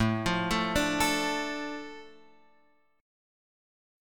A Suspended 4th Sharp 5th